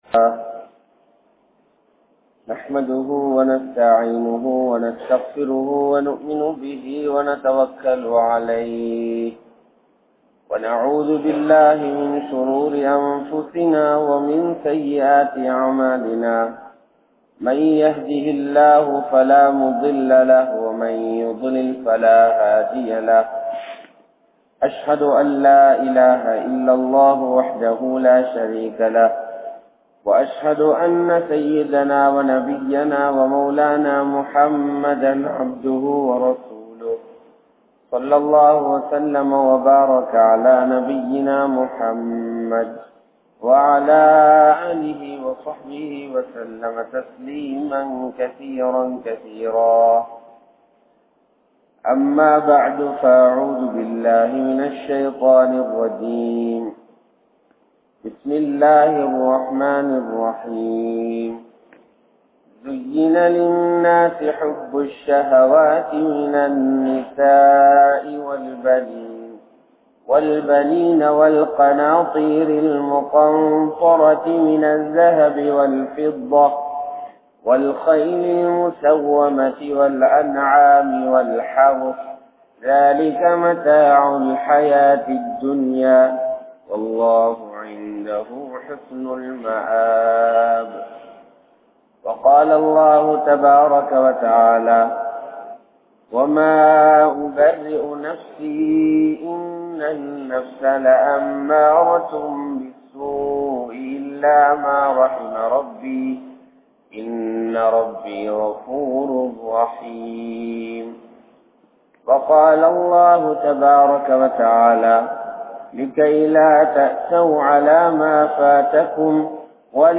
Manithanudaiya Aasaien Vilaivu | Audio Bayans | All Ceylon Muslim Youth Community | Addalaichenai
Jamiul Khairath Jumua Masjith